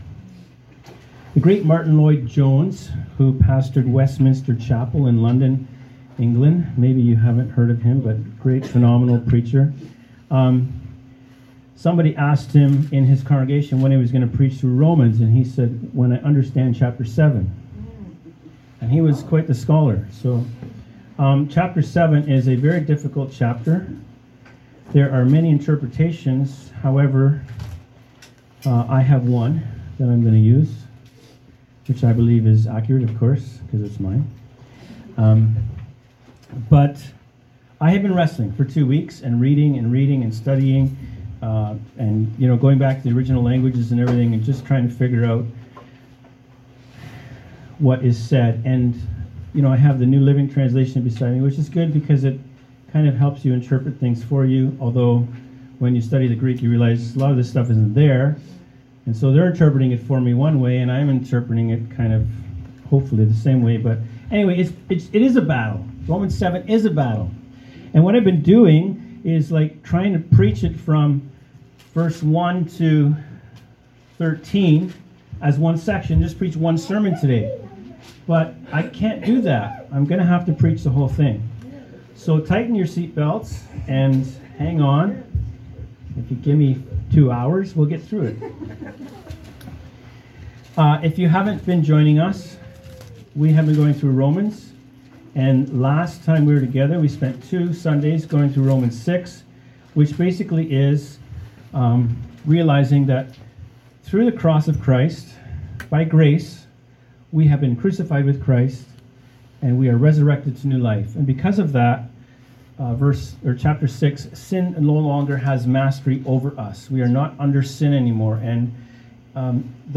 Audio Sermons - Freedom House Church and Healing Centre